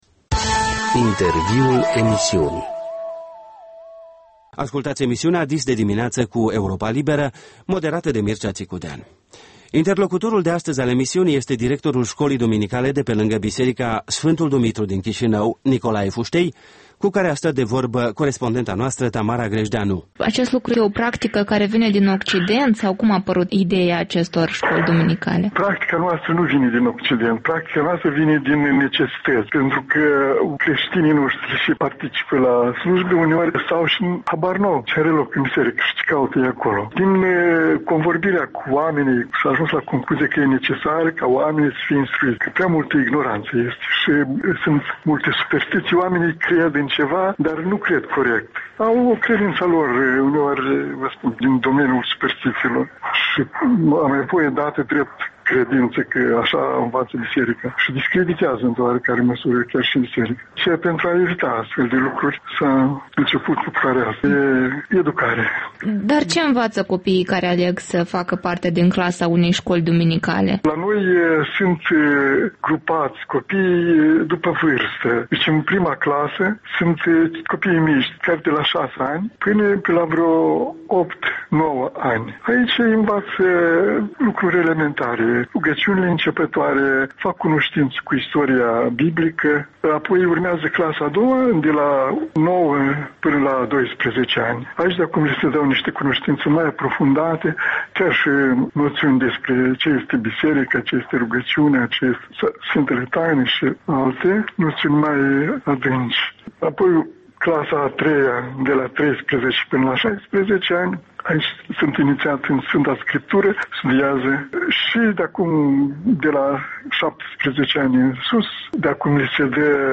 Interviu matinal